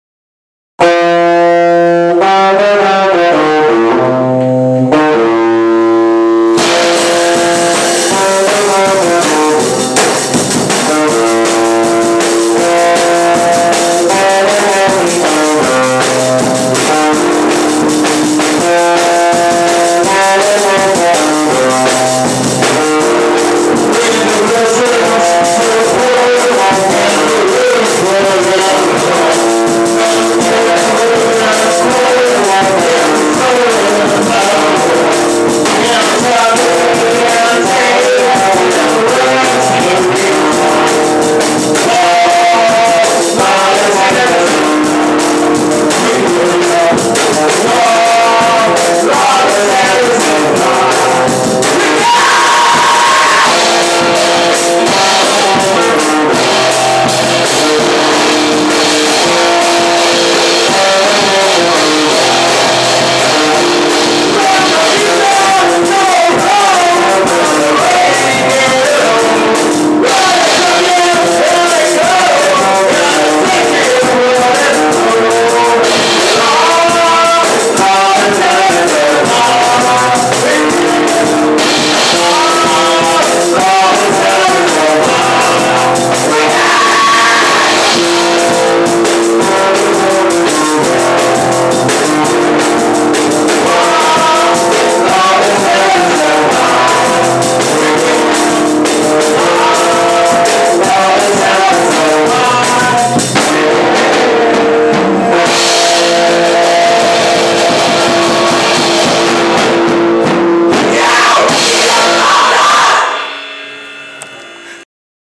cover song.